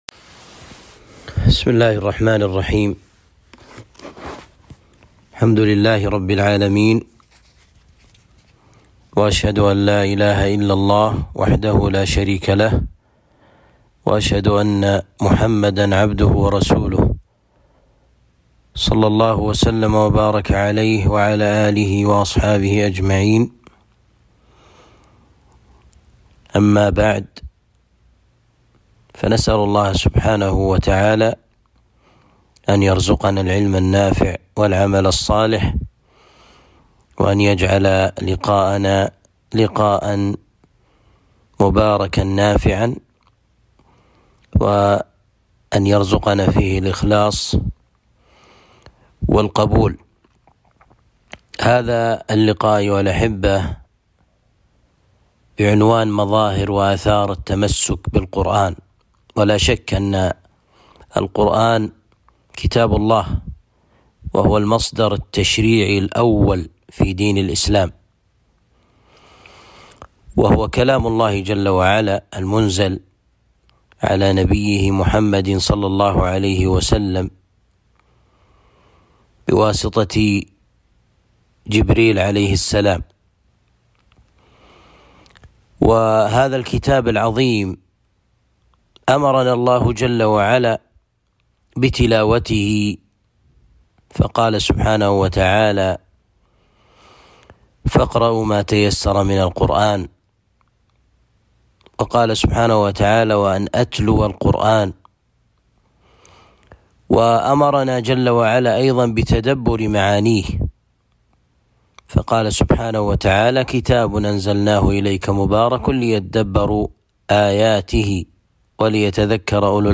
محاضرة بعنوان مظاهر وآثار التمسك بالقرآن